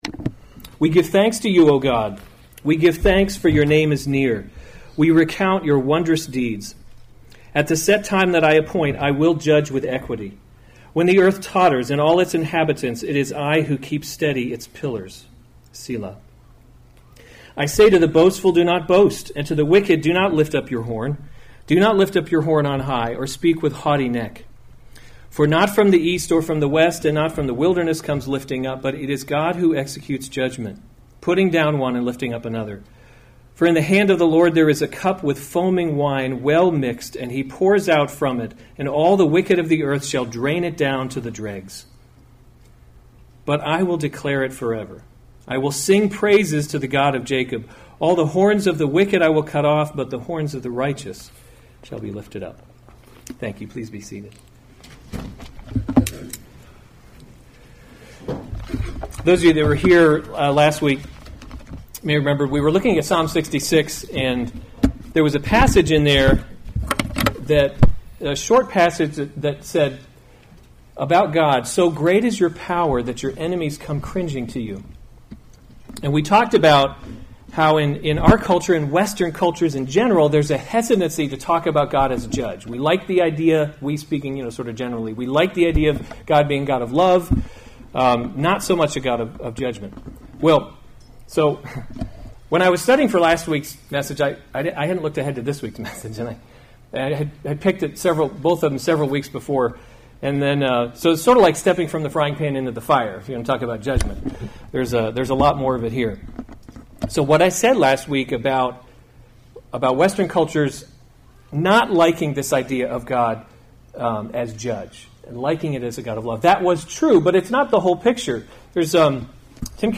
July 14, 2018 Psalms – Summer Series series Weekly Sunday Service Save/Download this sermon Psalm 75 Other sermons from Psalm God Will Judge with Equity To the choirmaster: according to […]